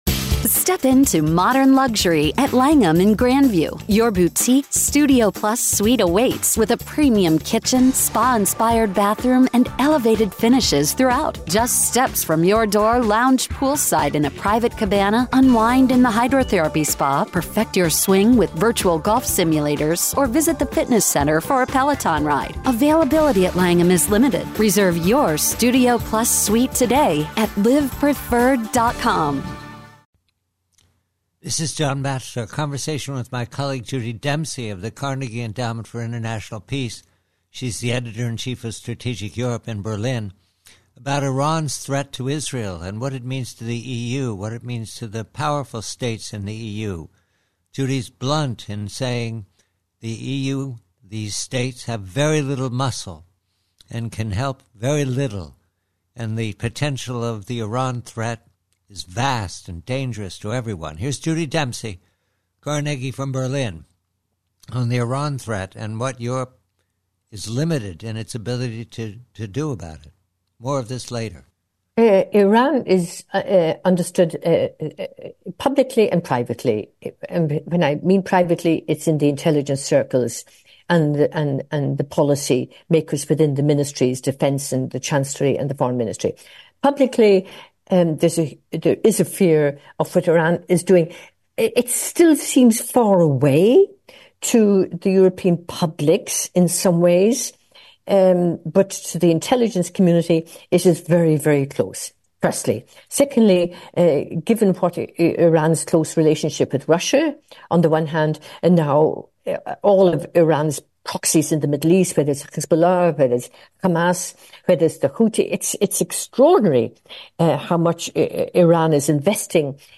PREVIEW: AFGHANISTAN: : Conversation with Mary Kissel regarding the Harris foreign policy with regard to the continuing threat of the Taliban and its allies and rivals, the jihadists.